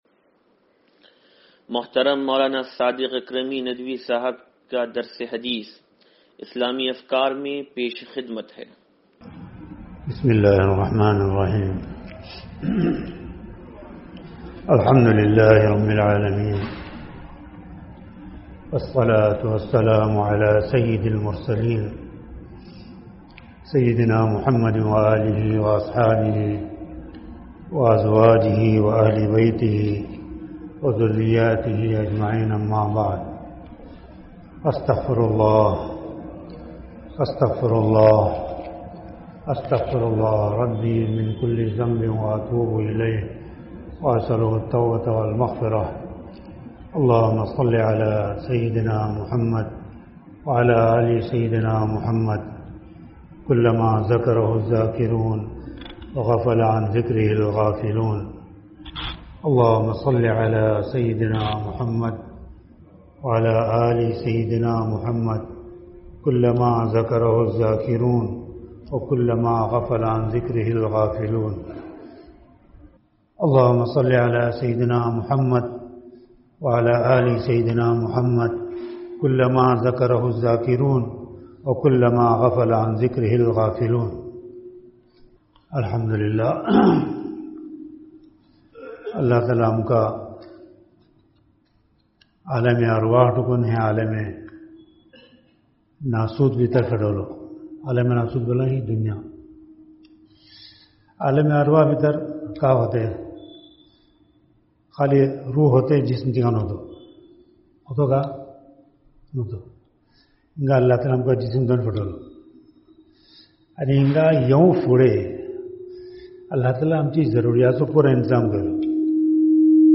درس حدیث نمبر 0632